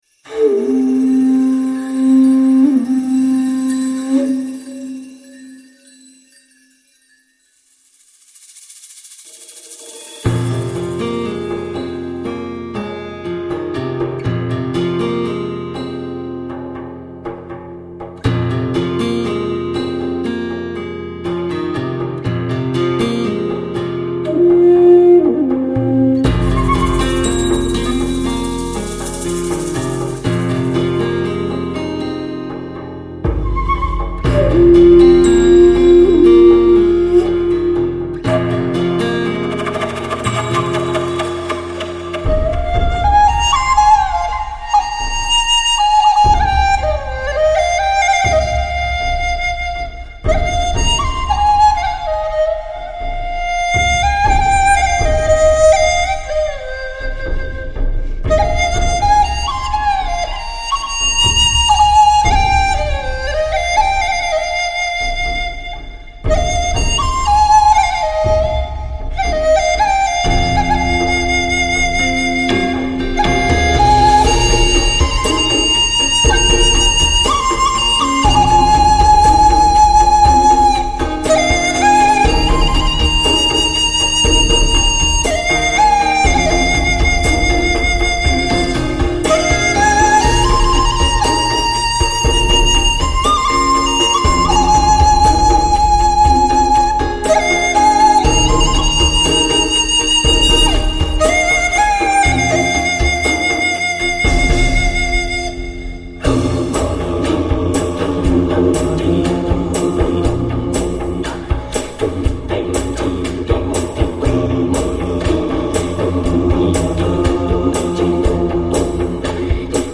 专辑格式：DTS-CD-5.1声道
雪域不老传奇西藏民族音乐之最
笛子主奏